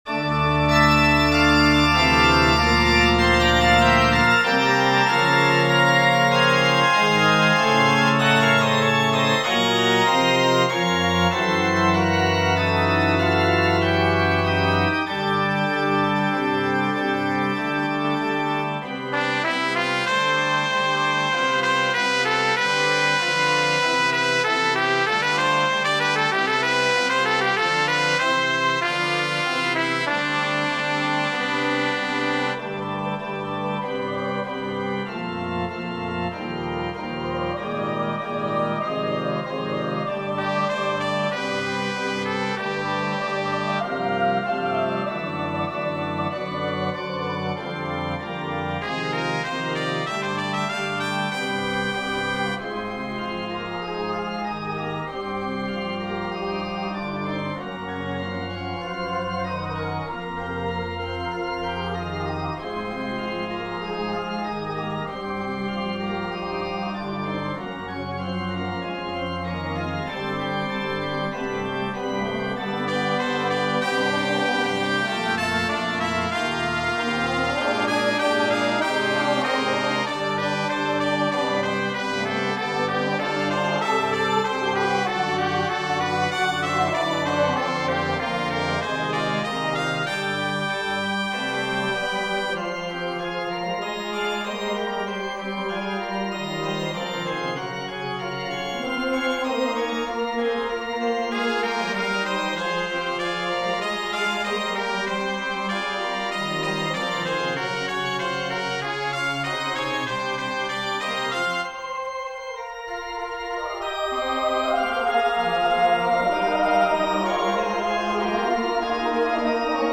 Georgia Douglas Johnsoncreate page Number of voices: 4vv Voicing: SATB Genre: Secular, Cantata
Language: English Instruments: Organ
Dona Nobis Pacem third movement for chorus and wind ensemble